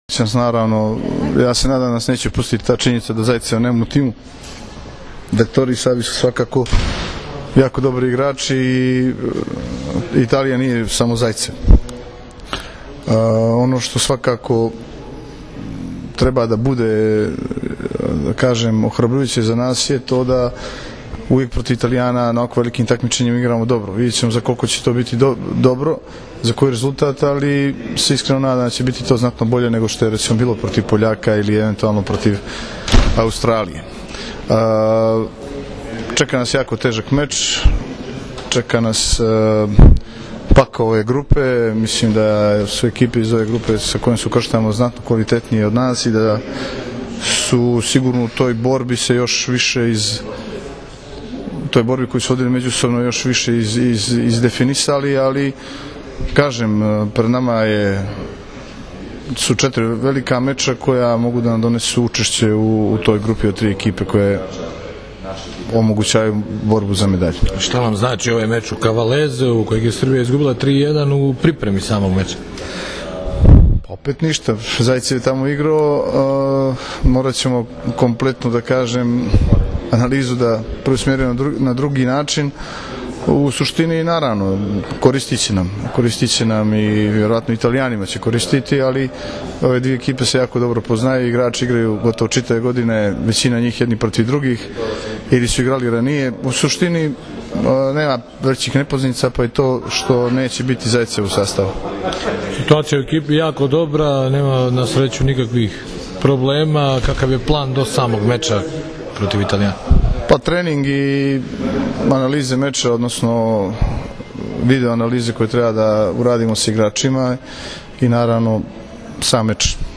Održana konferencija za novinare u Lođu – u sredu Srbija – Italija (16,40 – RTS 2)
IZJAVA